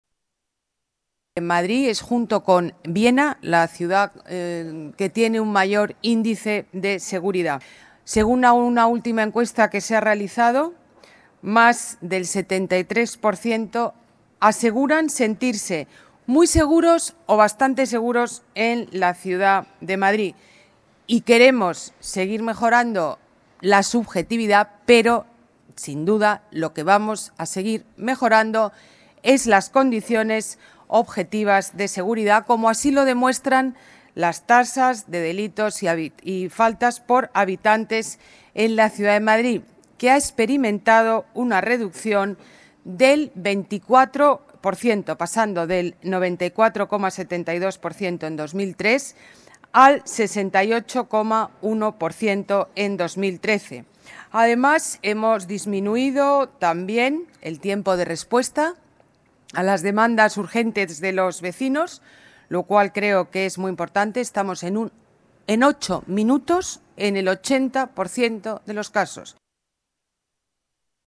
La alcaldesa ha presentado hoy en la Caja Mágica los 274 vehículos y las 84 nuevas motos
Nueva ventana:Declaraciones Ana Botella: renovación flota Policía Municipal